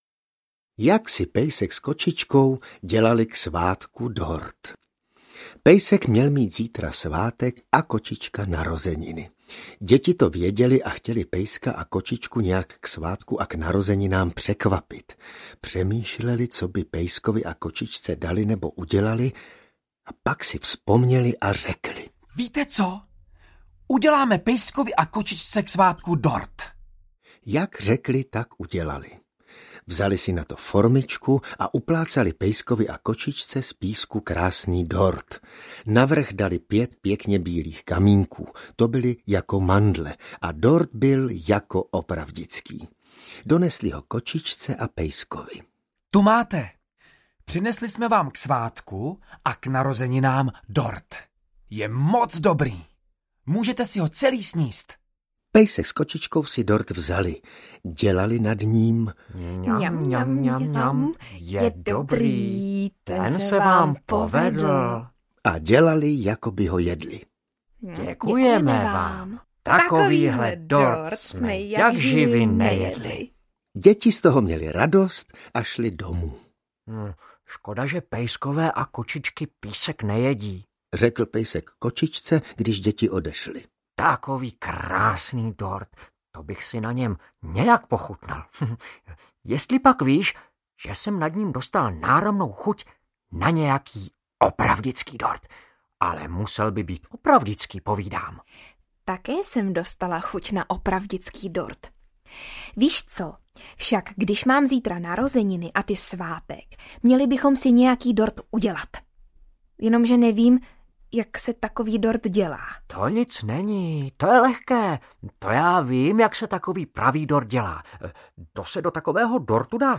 Povídání o pejskovi a kočičce audiokniha
Ukázka z knihy
• InterpretJaroslav Plesl, Veronika Khek Kubařová, Ivan Trojan, Hynek Čermák, Martin Myšička, Václav Neužil, Klára Issová, David Novotný, Lenka Krobotová, Zdeňka Žádníková Volencová